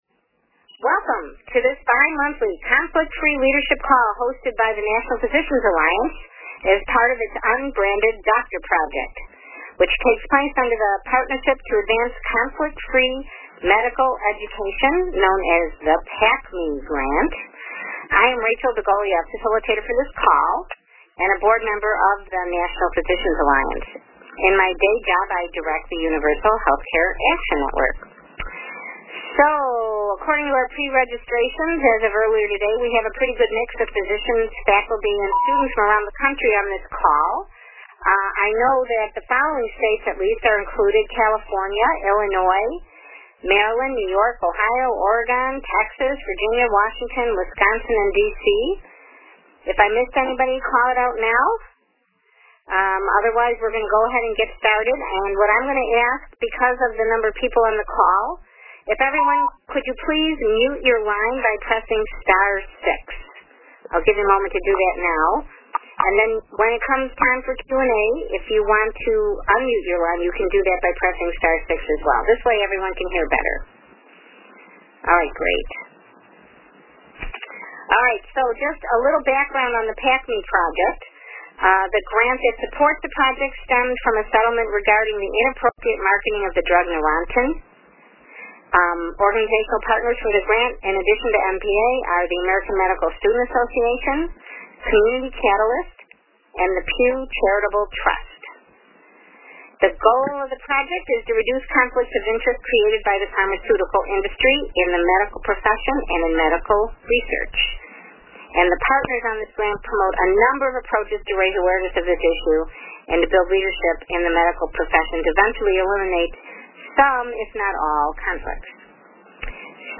This lecture was recorded on May 29, 2014.